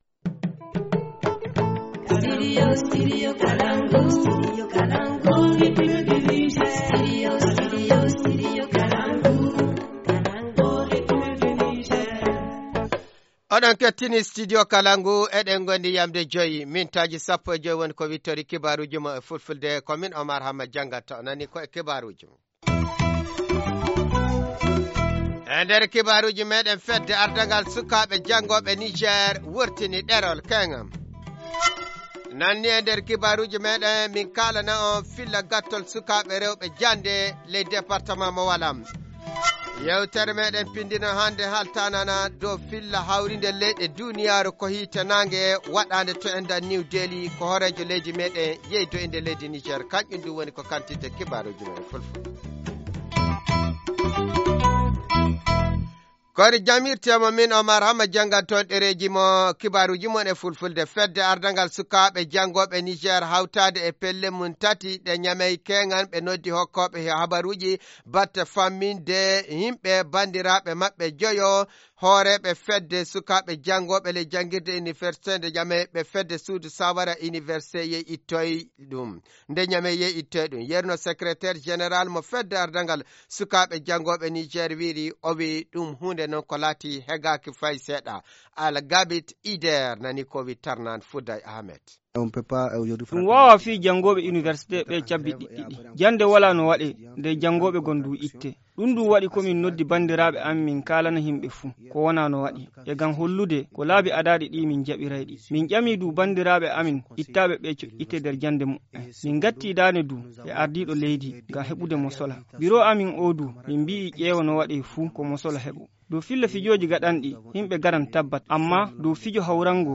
Journal du 16 mars 2018 - Studio Kalangou - Au rythme du Niger